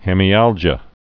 (hĕmē-ăljə)